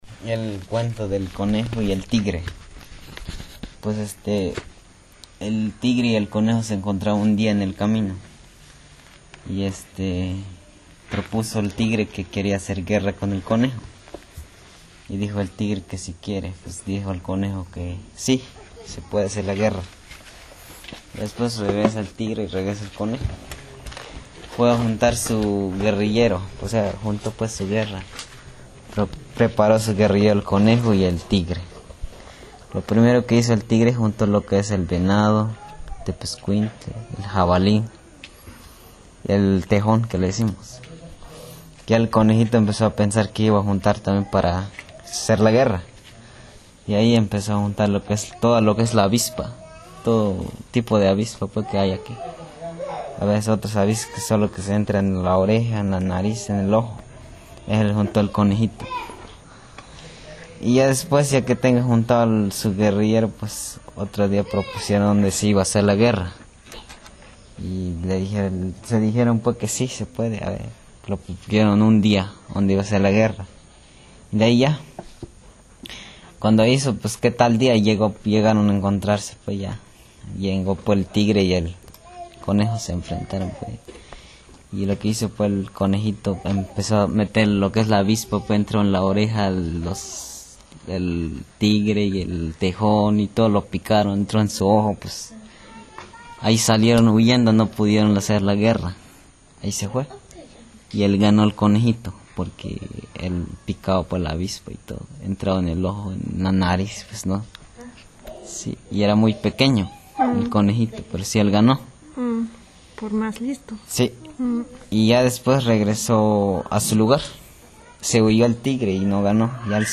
When it was told we were sitting in front of the mural; adults and children from the community, from several cities in Mexico, from several countries.